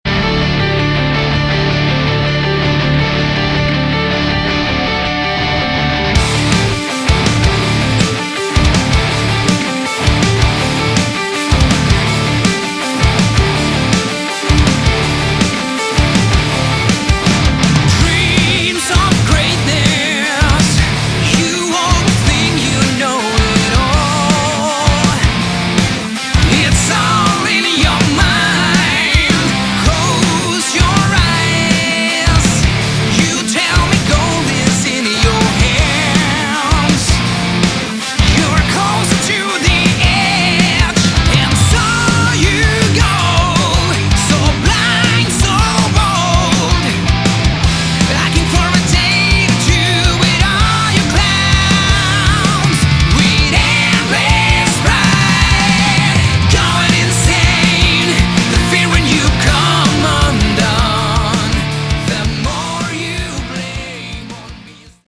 Category: Melodic Metal
vocals
guitars
drums
bass